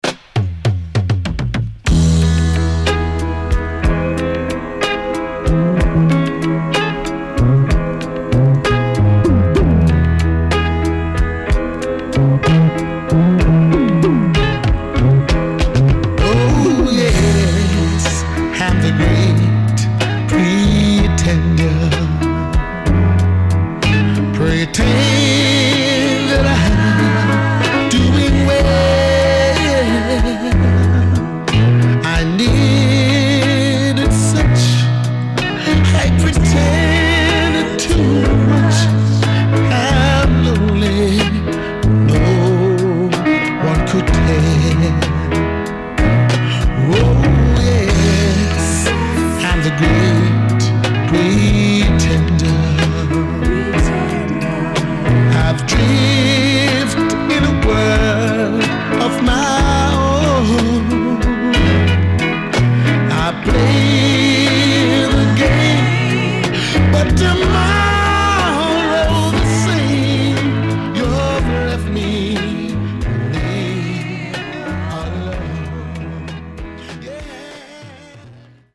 SIDE A:少しチリノイズ入ります。